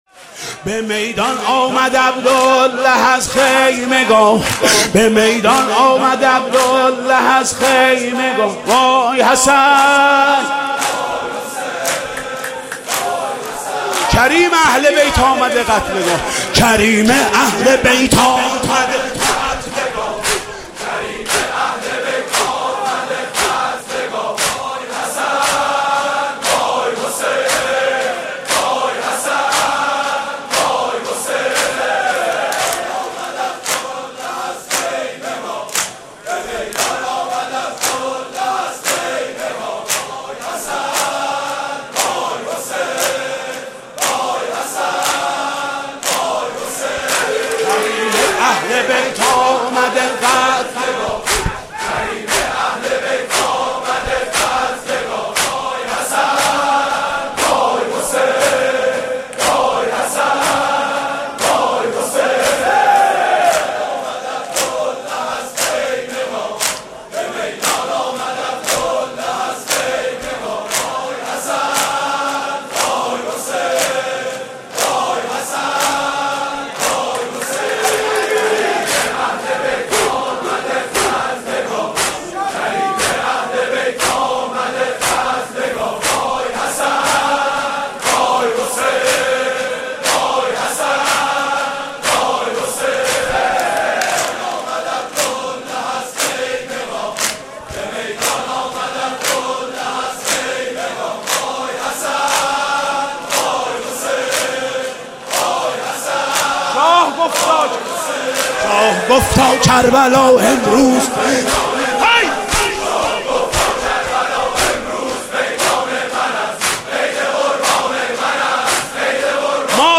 مراسم شب پنجم محرم الحرام سال 1395 با نوای محمود کریمی.